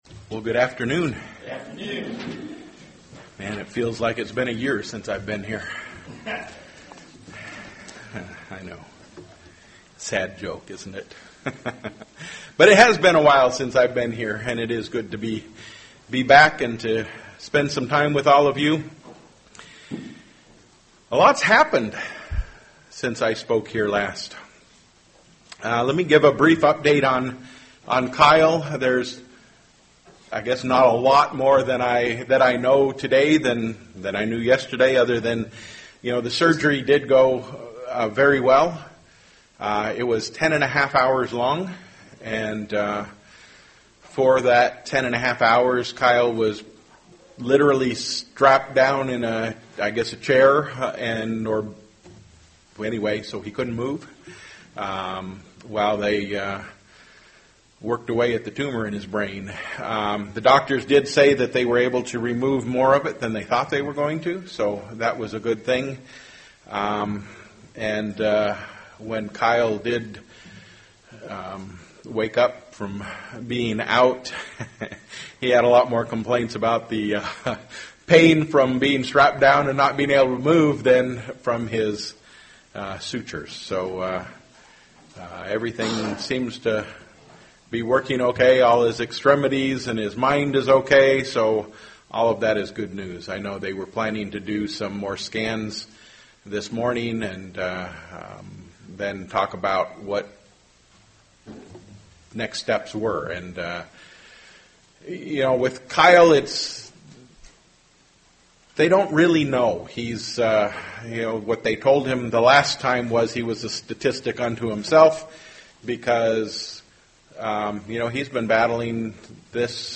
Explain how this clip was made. Given in Flint, MI